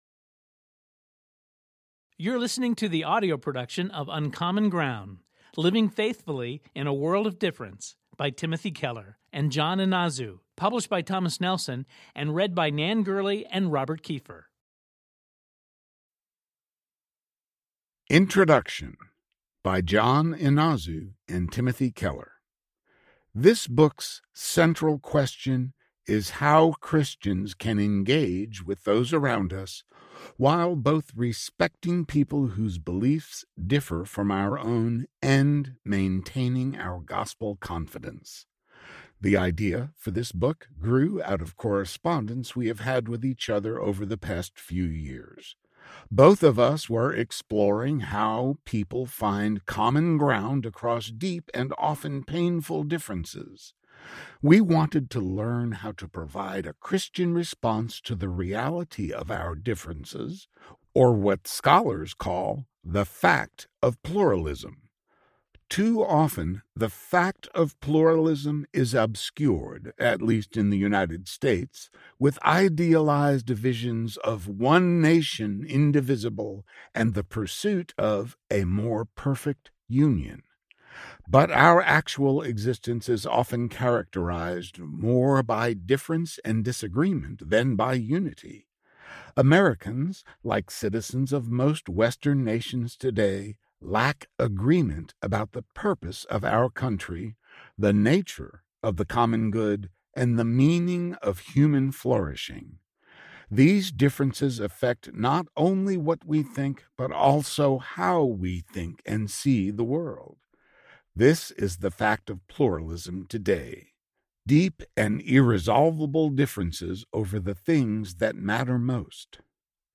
Uncommon Ground Audiobook